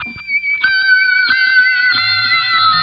Index of /90_sSampleCDs/Zero-G - Total Drum Bass/Instruments - 2/track42 (Guitars)